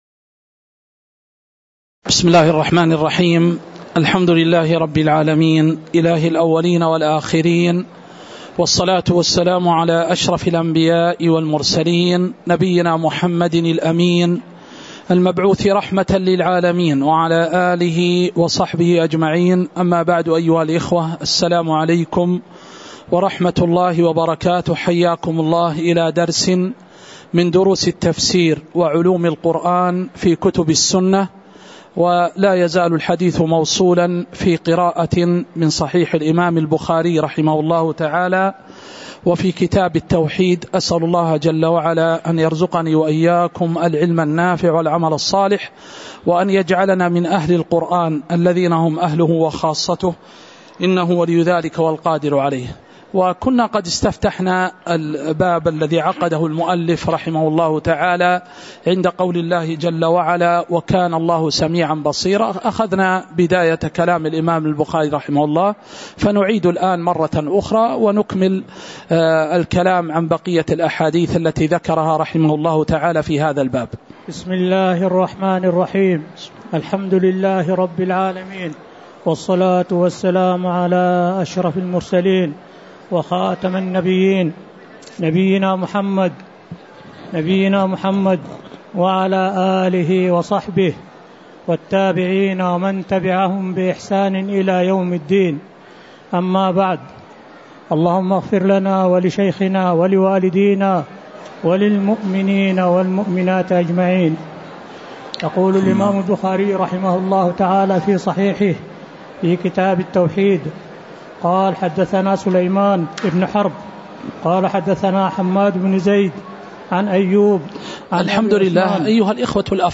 الدروس العلمية بالمسجد الحرام والمسجد النبوي